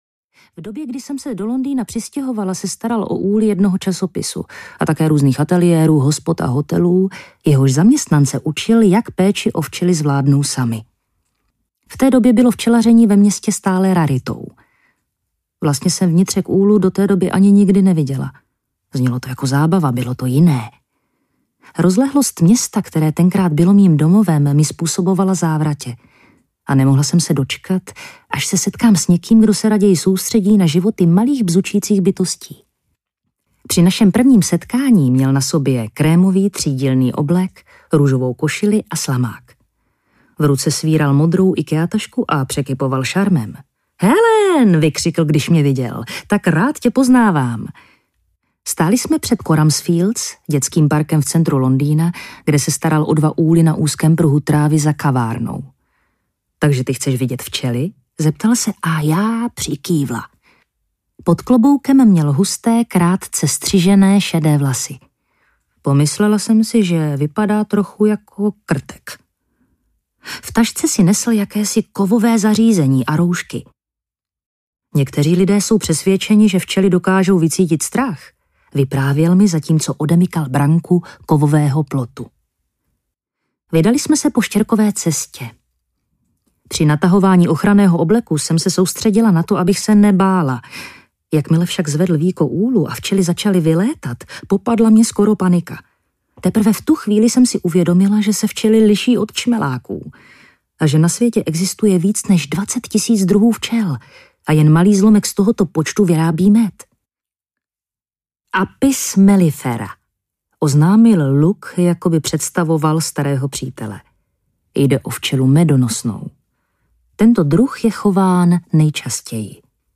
Srdce včely má pět komor audiokniha
Ukázka z knihy